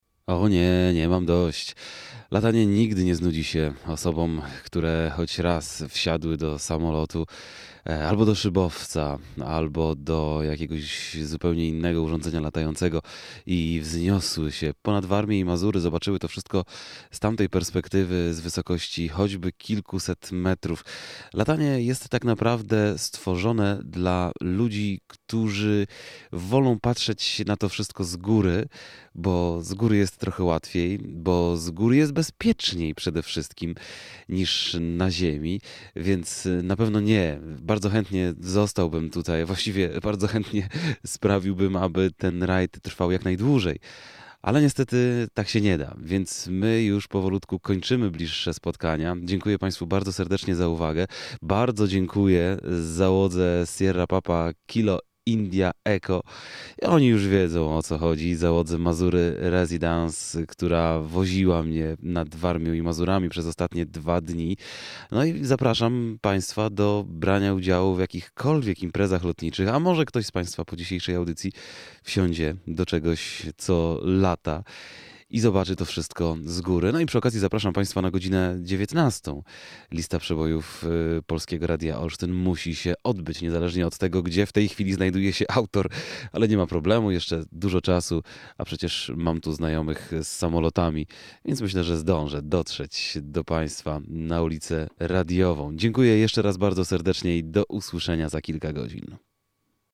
2009-06-13Relacja z Rajdu po Lotniskach Warmii i Mazur - pożegnanie. (źródło: Radio Olsztyn)